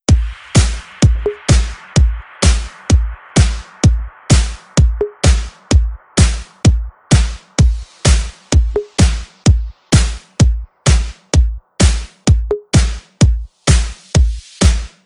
techno_beat.wav